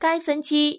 ivr-for_this_person.wav